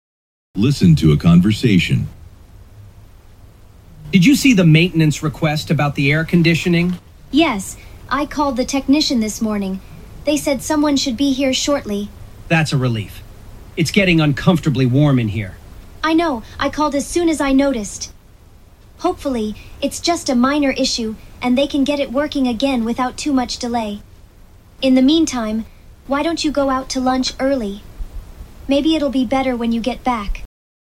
ここでの音声はもともと研究用に録音されたものであり、音質があまり良くない点はご容赦ください。